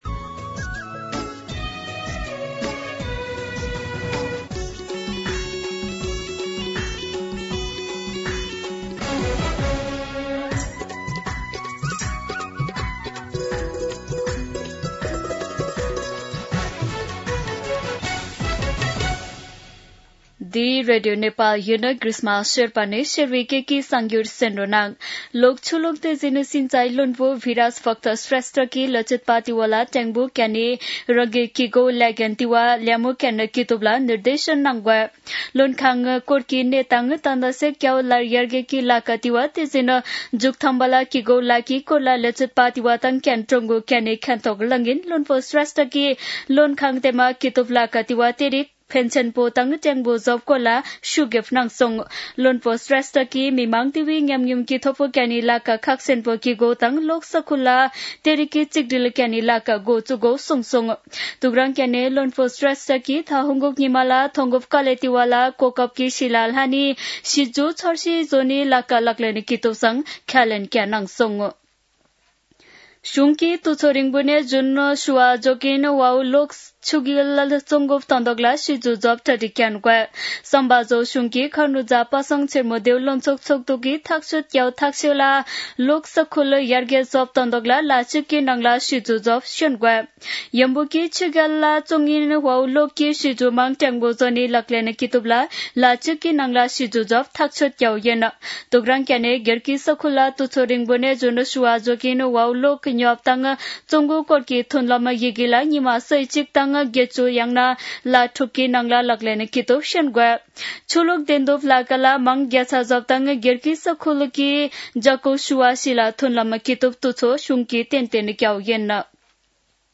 शेर्पा भाषाको समाचार : १५ चैत , २०८२